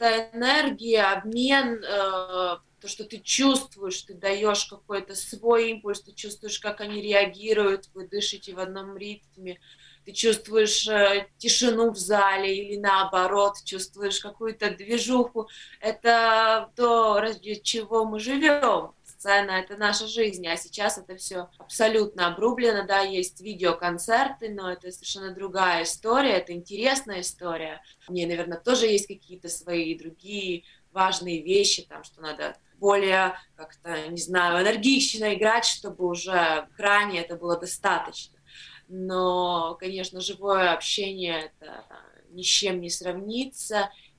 На радио Baltkom сегодня обсуждали такие темы как онлайн-концерты и занятия спортом в период пандемии, а также возможность получить финансовую поддержку на реализацию идей.
Онлайн-концерты не могут полноценно заменить музыкантам и слушателям реальные выступления, рассказала в эфире радио Baltkom певица и пианистка